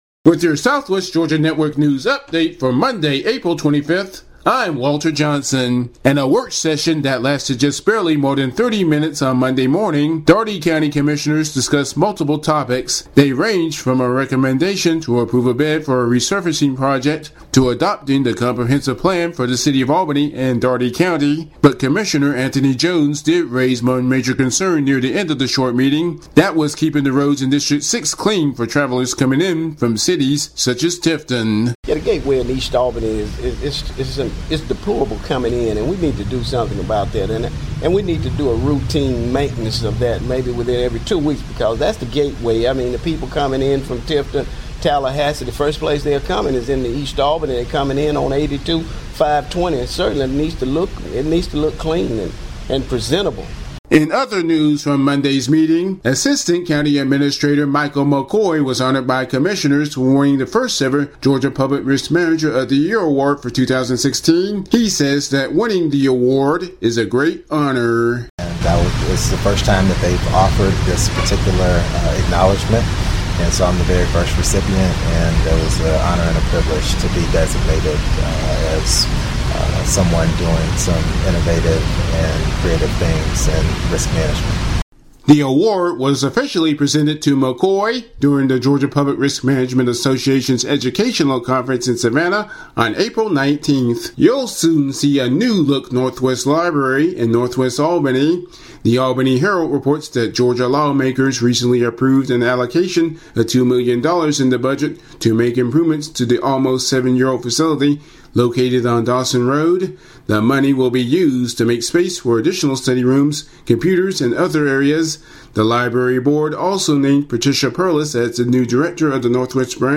Audio News Update For Monday, April 25, 2016